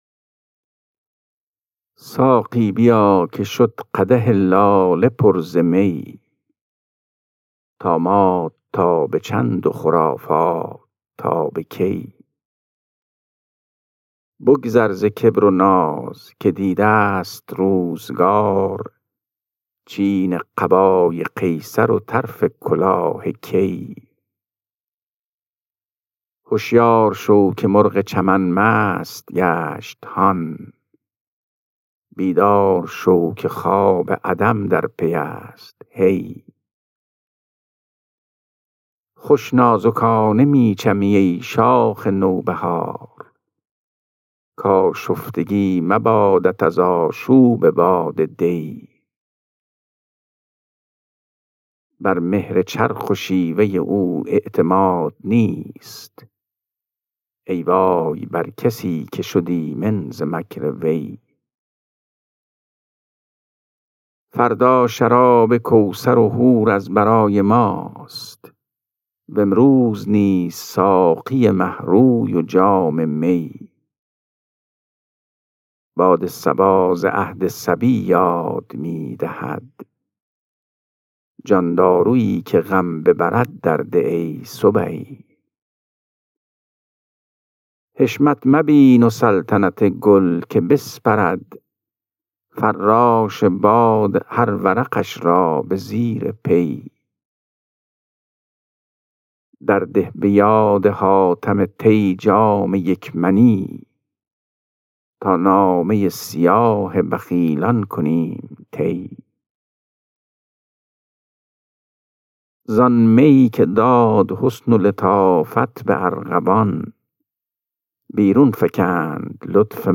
خوانش غزل شماره 429 دیوان حافظ